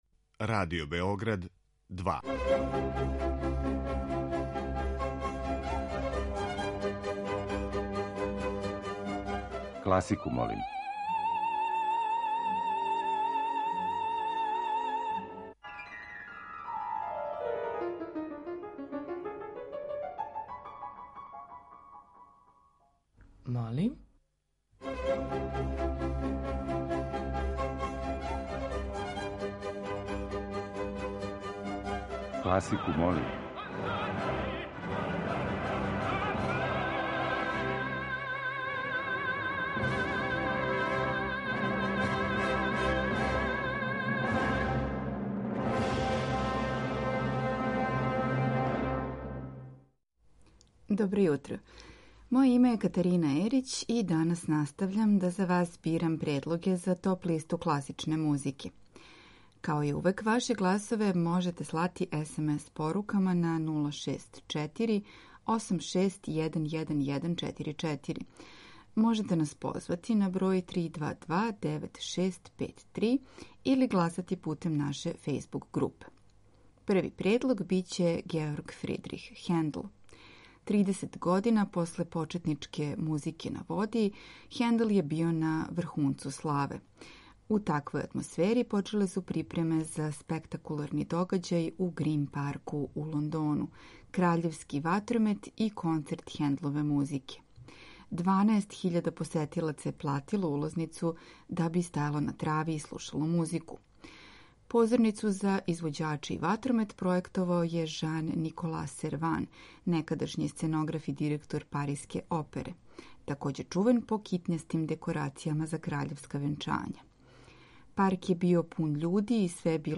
Весели, нежни, узбудљиви и забавни звуци одвешће вас звуком у златно доба Париског балета. Слушаоци бирају хит недеље озбиљне музике.